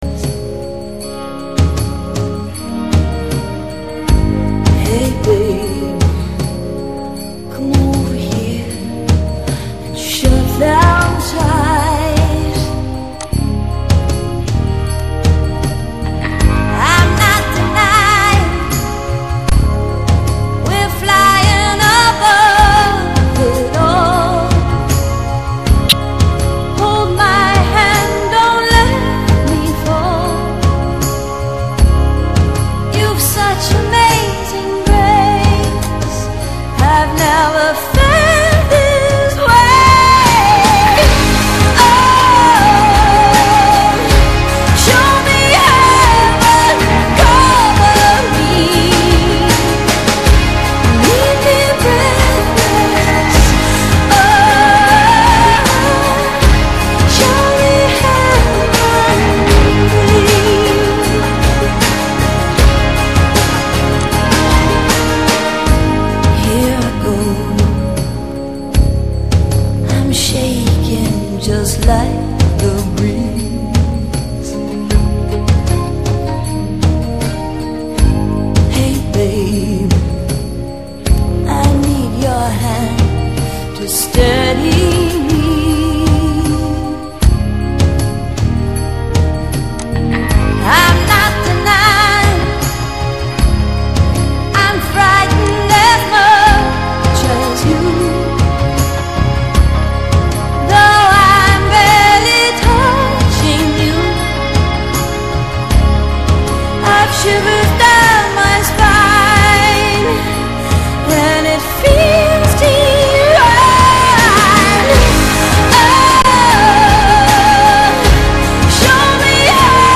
random mixtape 2008_1017.mp3